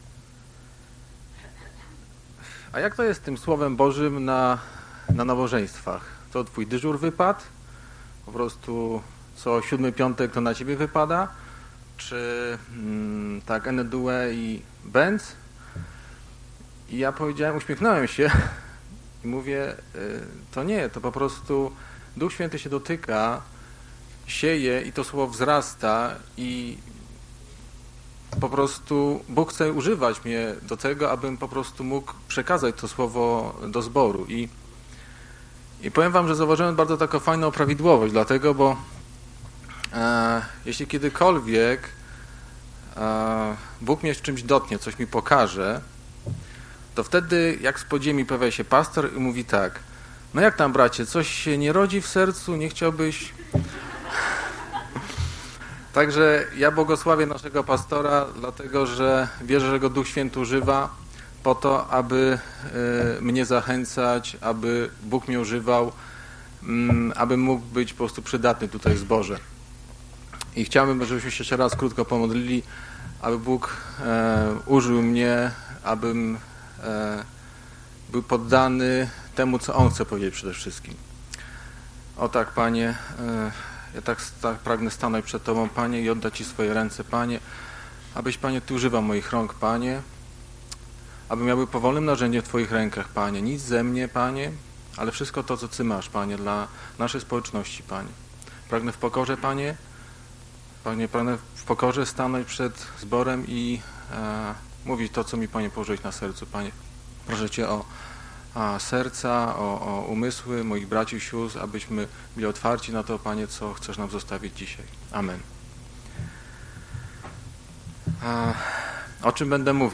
Konferencja dla duchownych w Woli Piotrowej